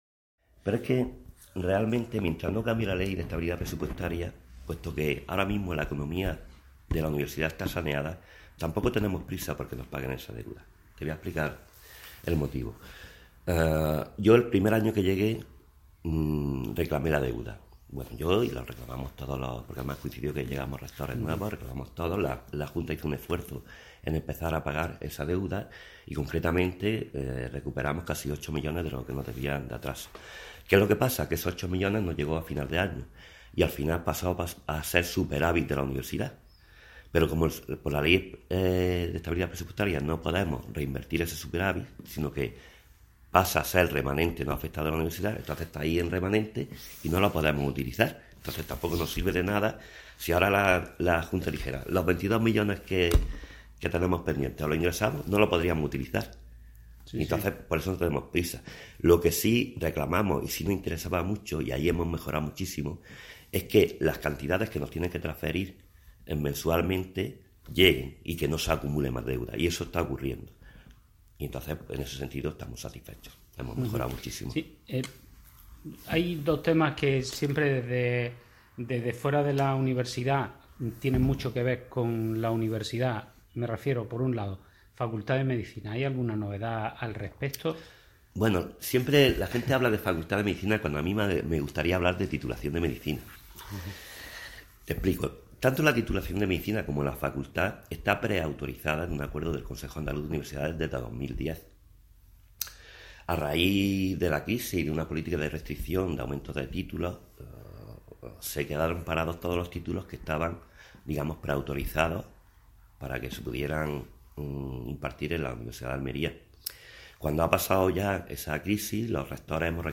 Entrevistas Ampliar Carmelo Rodríguez: En menos de un año la UAL tendrá sede en el centro de la capital facebook twitter google+ Comentar Imprimir Enviar Add to Flipboard Magazine.